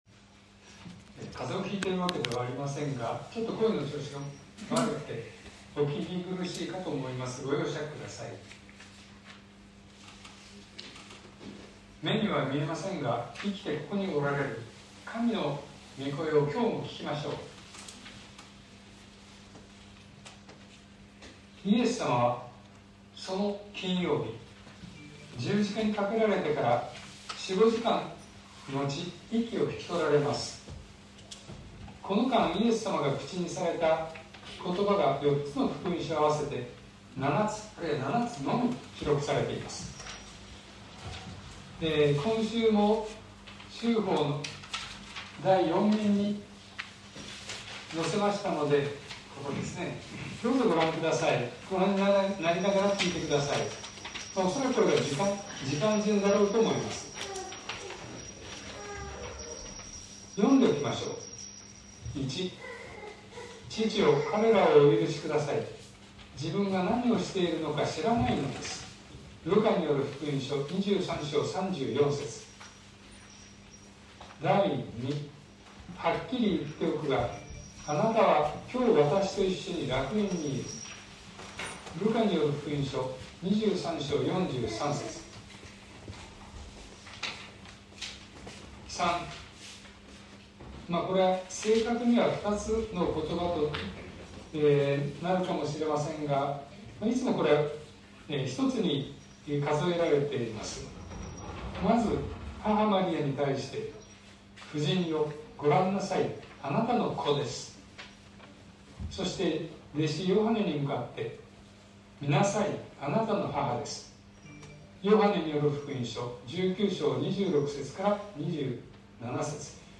2023年05月14日朝の礼拝「十字架上のイエスの言葉（２）」東京教会
説教アーカイブ。